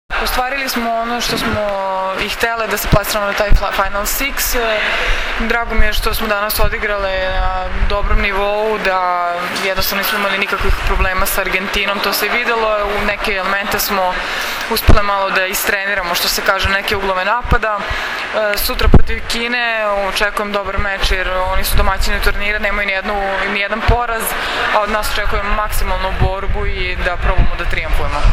IZJAVA JOVANE BRAKOČEVIĆ, KOREKTORA SRBIJE